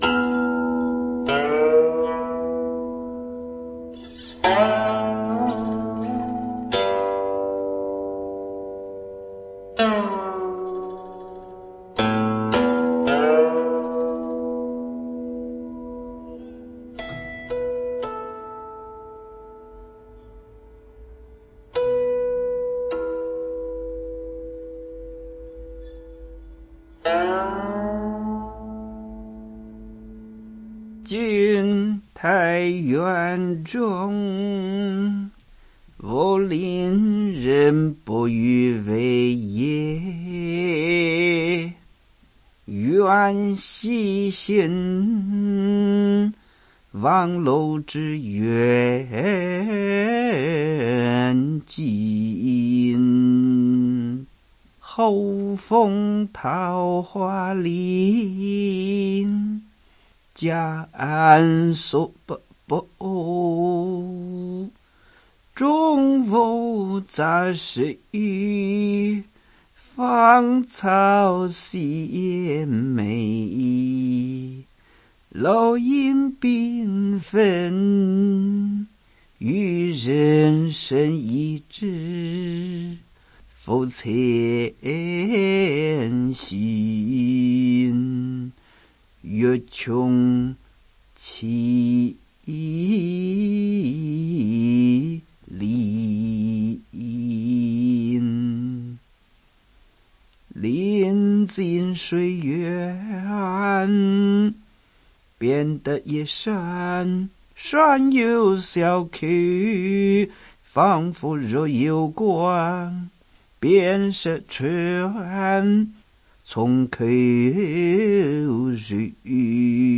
誦唸